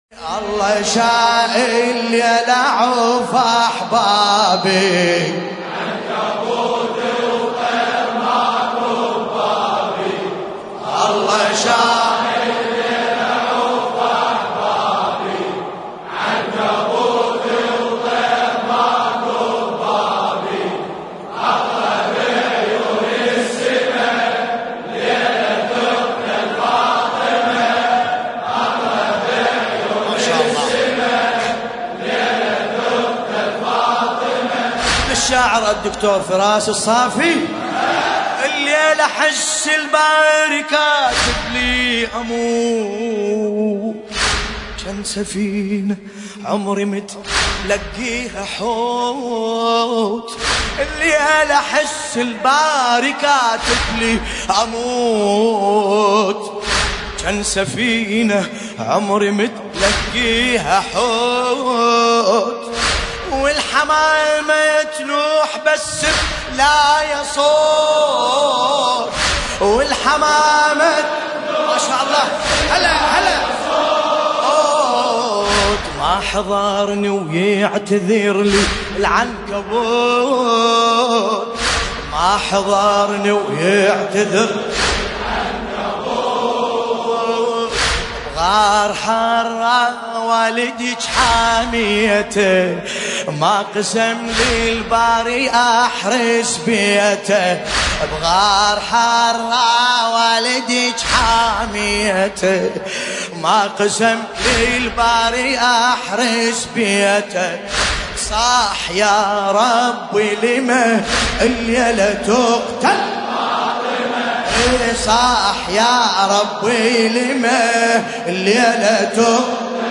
الرادود : الحاج ملا باسم الكربلائي
المناسبة : الليالي الفاطمية 1440